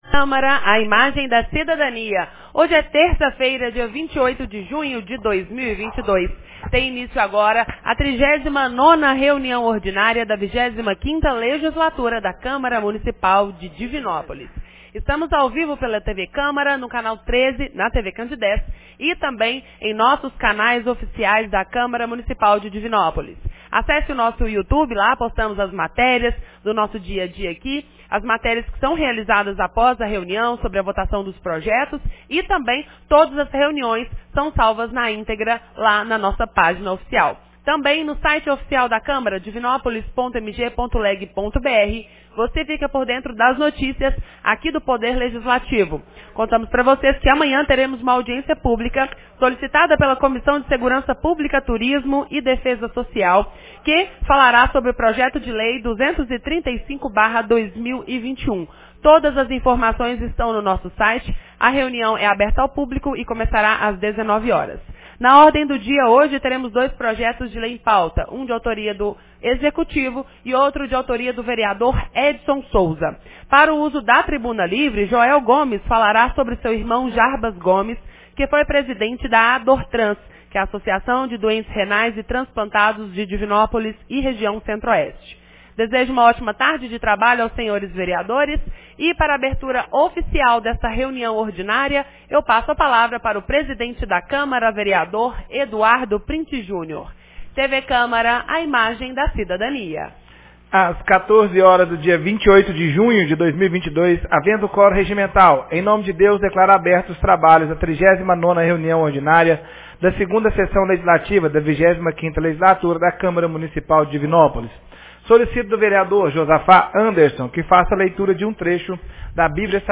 39ª Reunião Ordinária 28 de junho de 2022